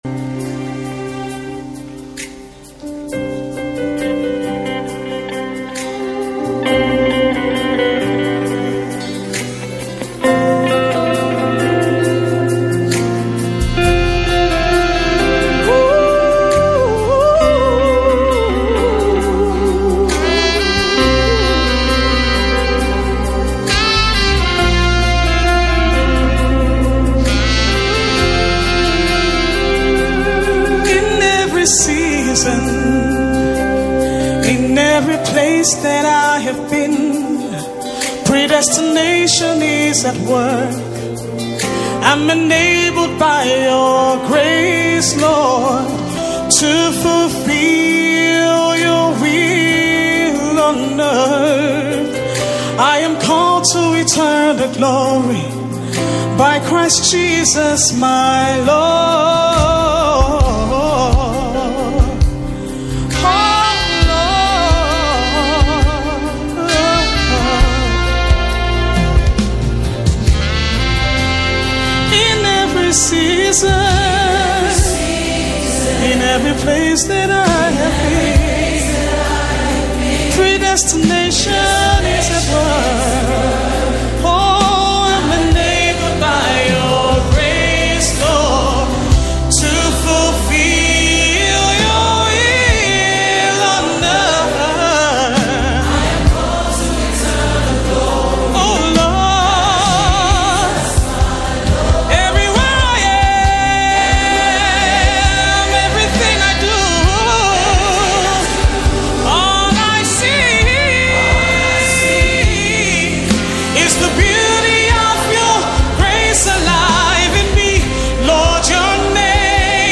Key C